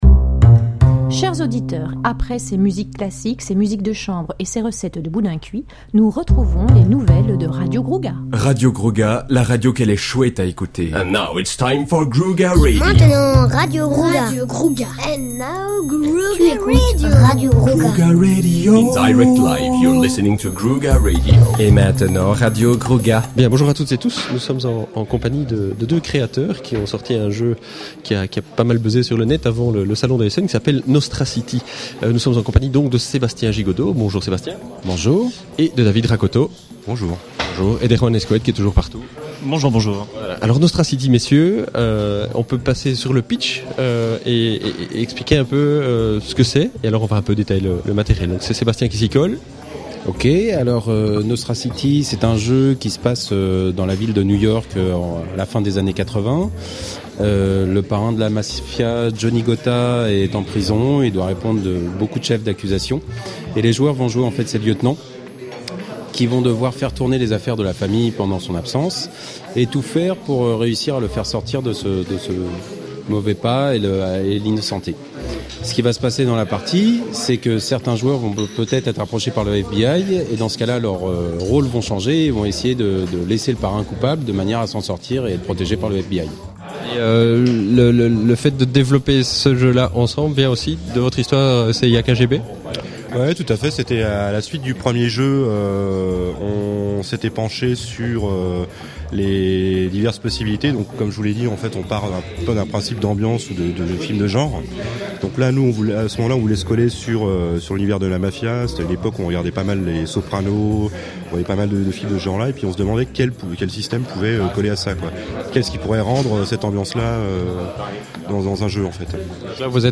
(enregistré au salon Spiel de édition 2009)